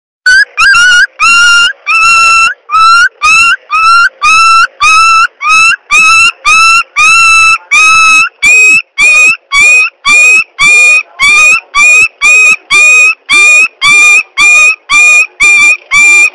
دانلود آهنگ حیوانات 45 از افکت صوتی انسان و موجودات زنده
جلوه های صوتی
دانلود صدای حیوانات 45 از ساعد نیوز با لینک مستقیم و کیفیت بالا
برچسب: دانلود آهنگ های افکت صوتی انسان و موجودات زنده دانلود آلبوم مجموعه صدای حیوانات مختلف با سبکی خنده دار از افکت صوتی انسان و موجودات زنده